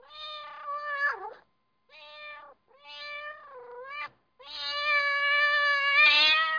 دانلود صدای گربه برای کودکان از ساعد نیوز با لینک مستقیم و کیفیت بالا
جلوه های صوتی